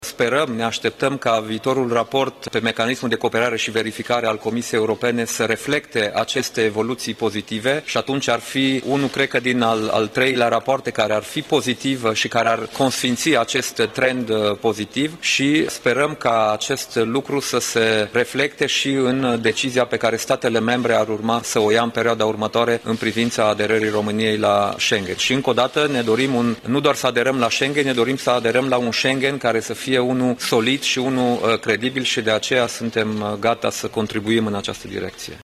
Într-o conferință de presă comună, șeful guvernului de la București a declarat că în acest moment se așteaptă raportul pe Justiție al Uniunii Europene, lupta împotriva corupției fiind un criteriu pentru aderarea României la Schengen: